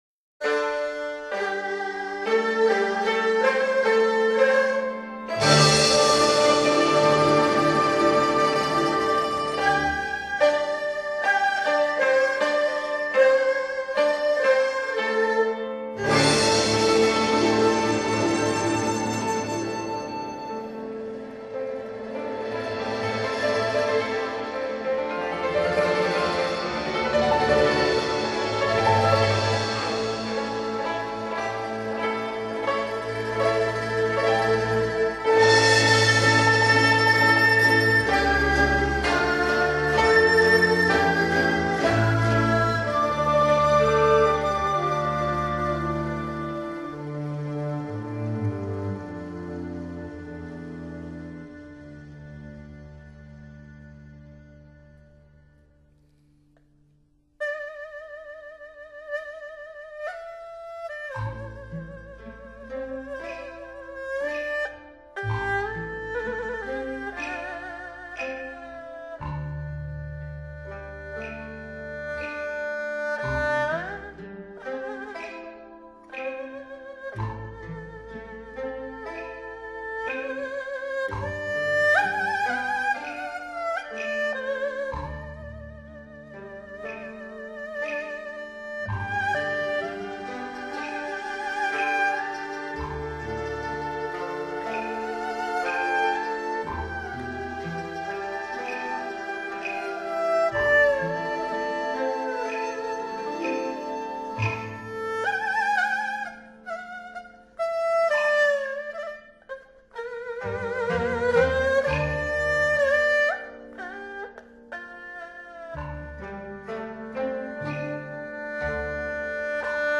扬琴
钢琴
吉它
低音大提琴